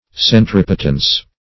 Centripetence \Cen*trip"e*tence\, n.